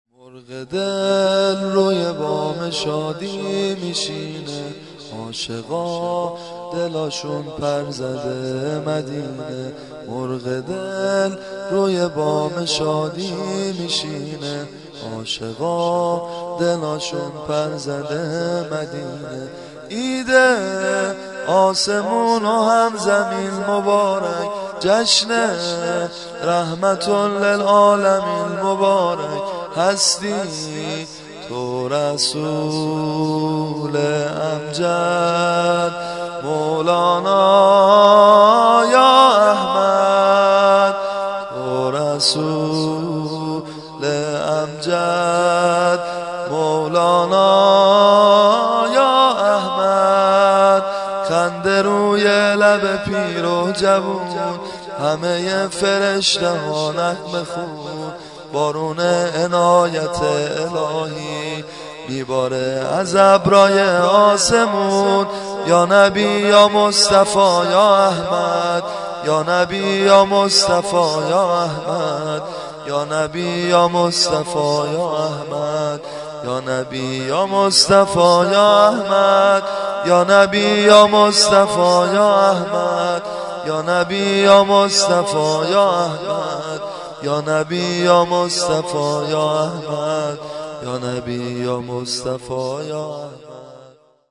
دانلود سبک سرود میلاد امام صادق و پیمبر اکرم با سبکی زیبا